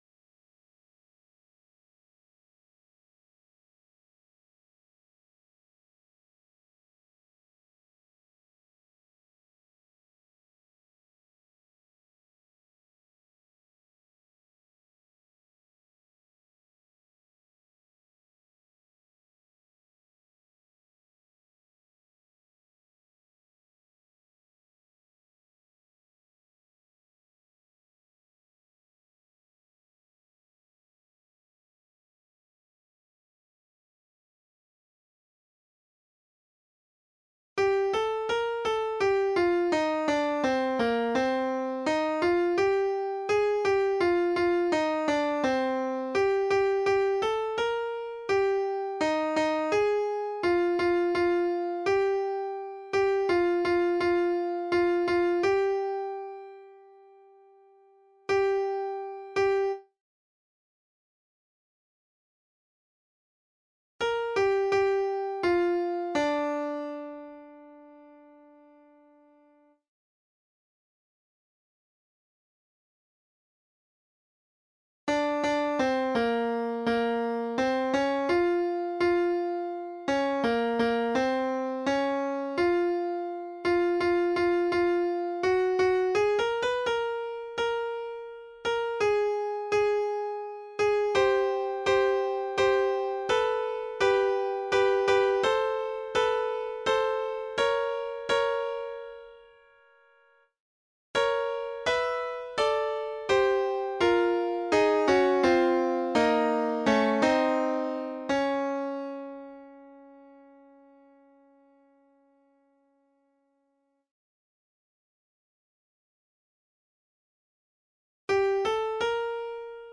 Voice part practice (alto)
like-a-lamb-alto-line.mp3